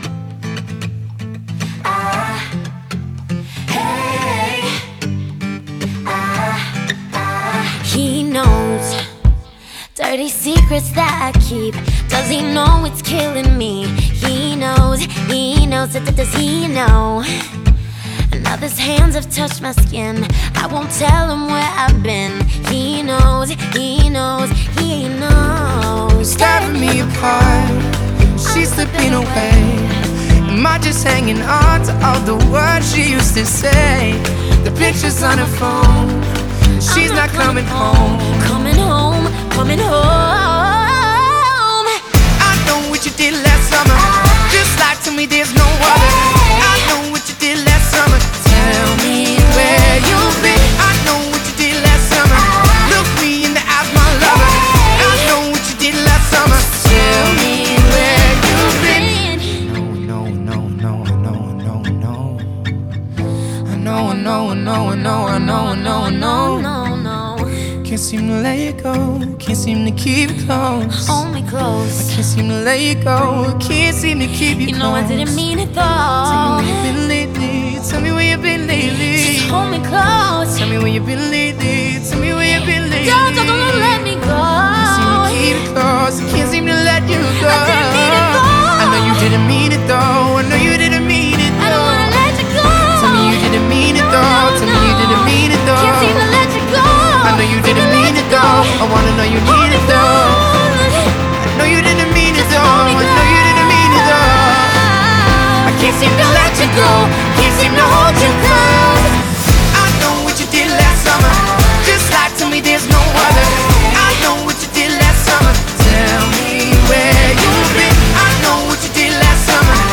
BPM114
Audio QualityLine Out